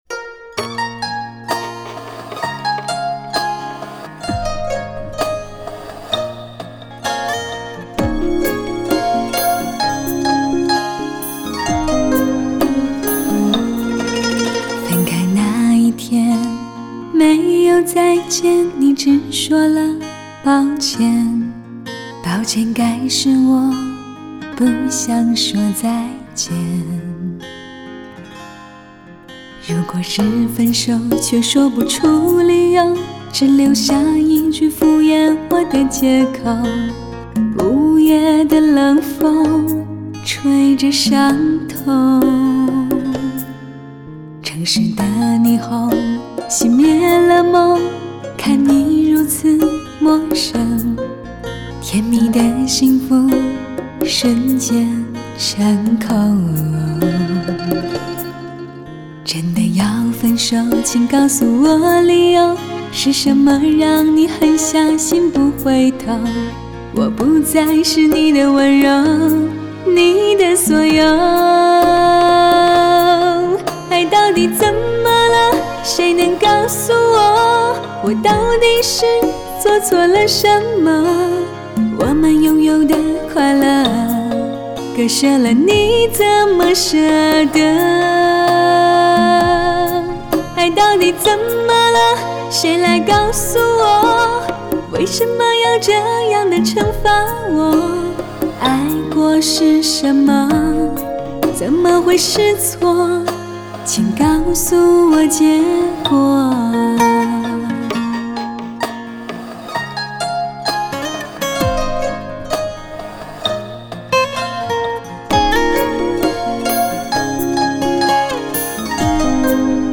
Жанр: Cinese Pop